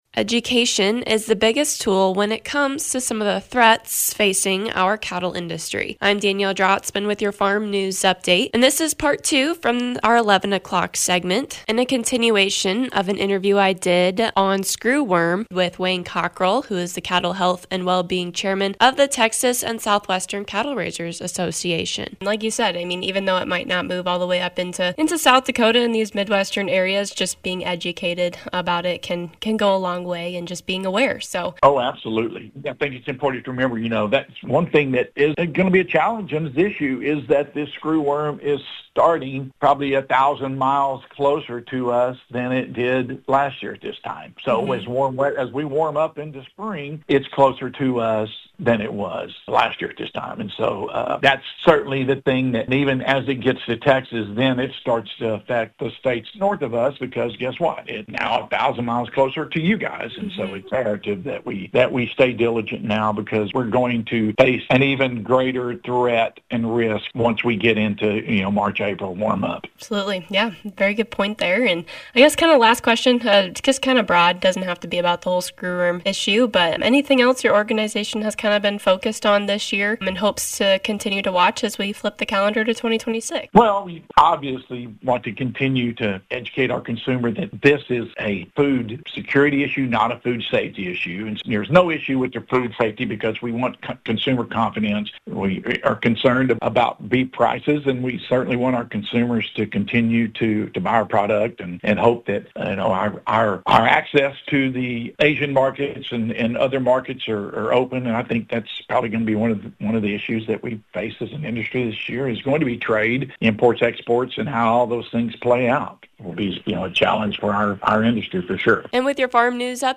More from the interview